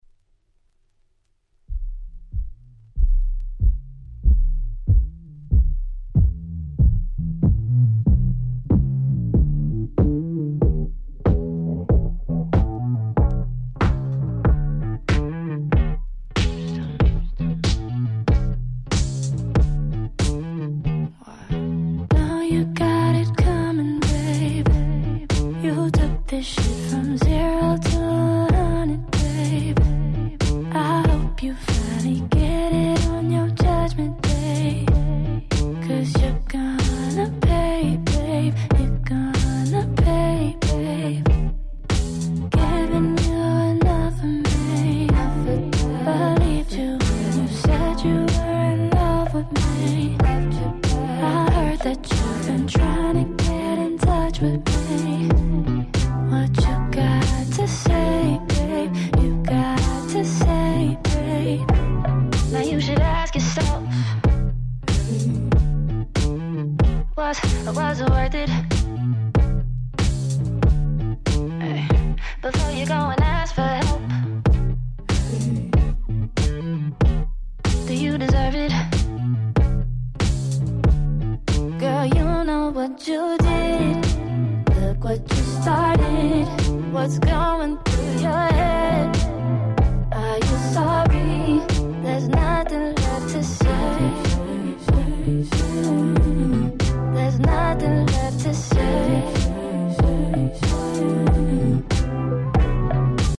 18' Smash Hit R&B/Neo Soul LP !!